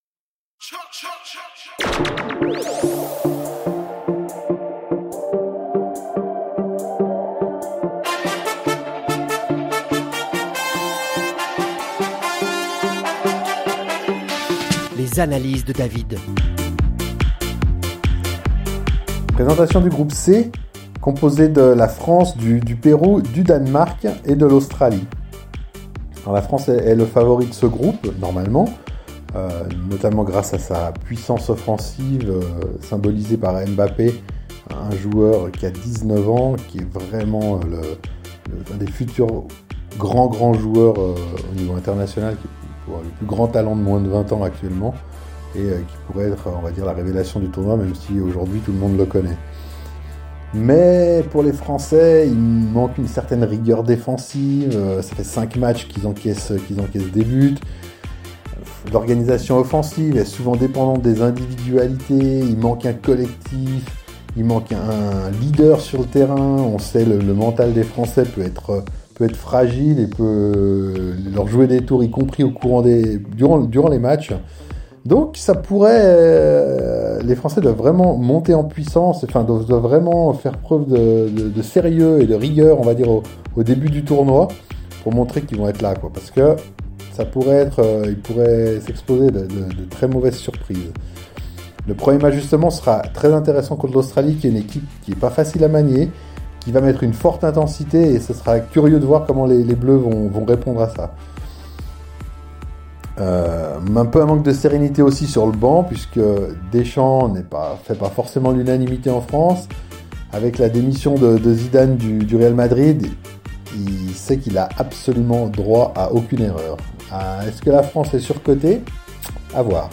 avec sarcasme et polémique.